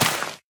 Minecraft Version Minecraft Version latest Latest Release | Latest Snapshot latest / assets / minecraft / sounds / block / sweet_berry_bush / break4.ogg Compare With Compare With Latest Release | Latest Snapshot